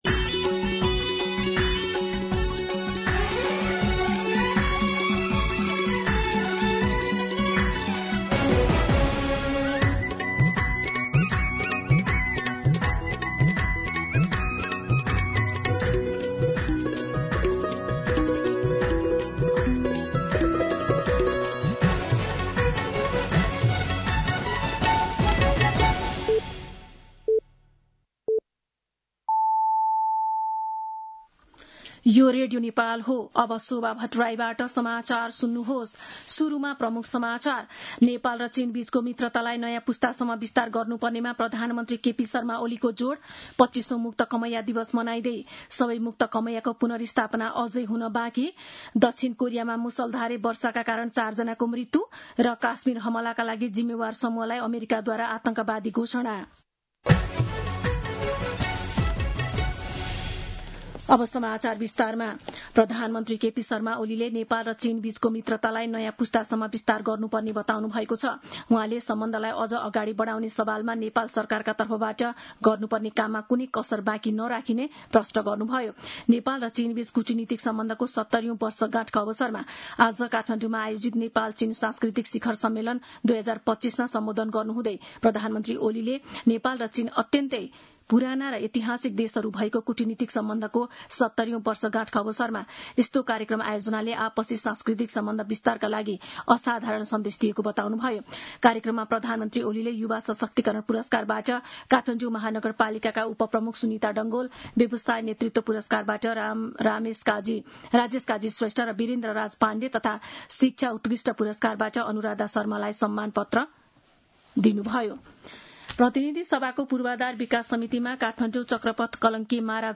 दिउँसो ३ बजेको नेपाली समाचार : २ साउन , २०८२